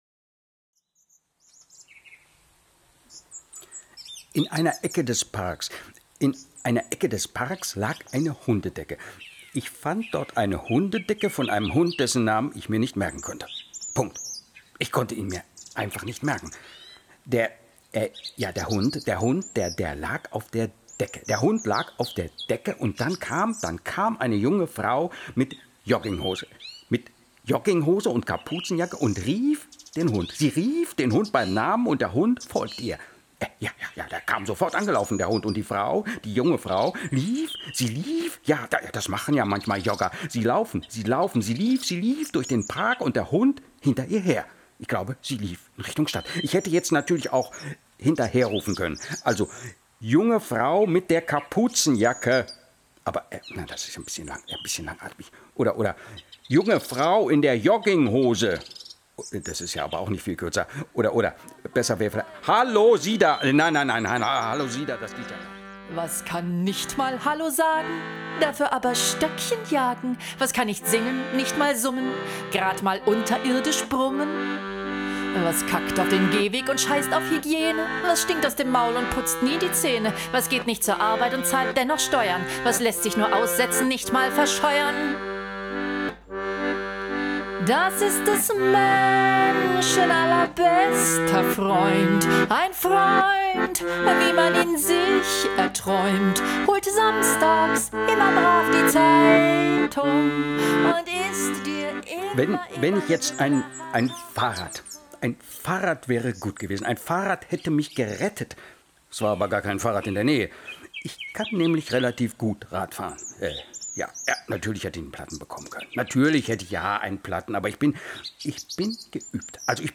In einer losen Szenenfolge werden die verschiedenen Begegnungen von Mann, Frau, Hund und Hut musikalisch-poetisch ausgelotet.